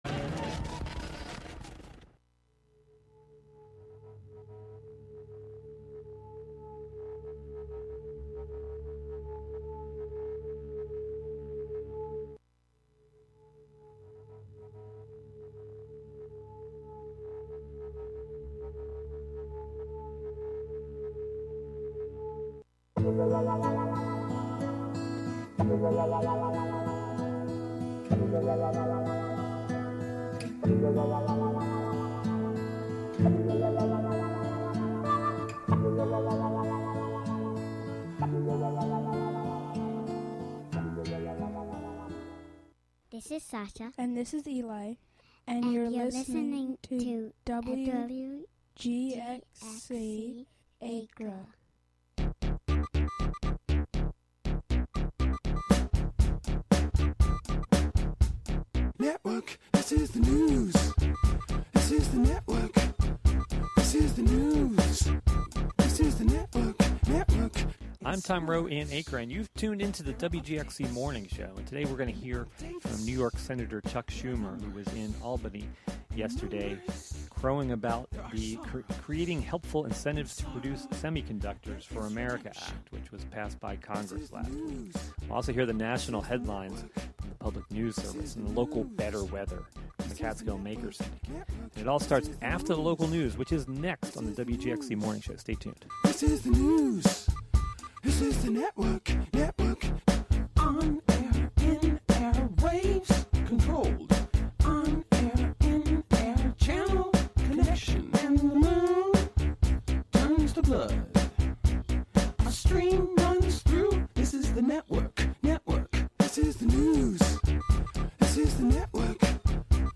On Monday Senator Charles Schumer was at the Albany NanoTech Complex discussing the impact this has on the capital region. His remarks are courtesy of a Facebook live stream from WNYT-News Channel 13.